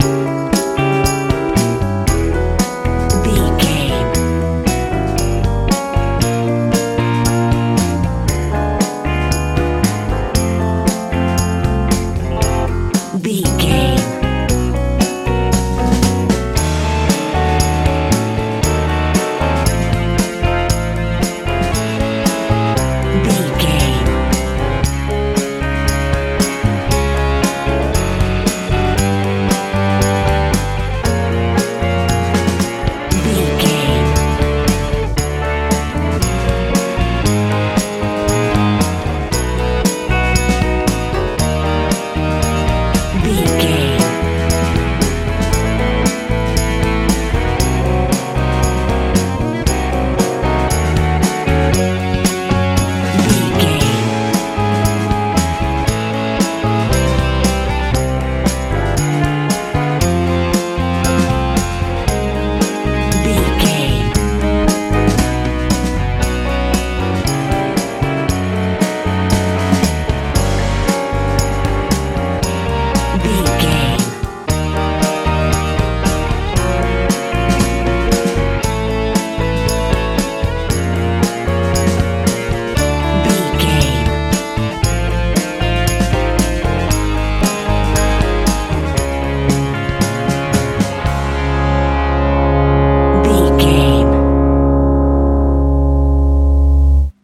worship feel
Ionian/Major
joyful
hopeful
piano
electric guitar
bass guitar
drums
sweet
playful
calm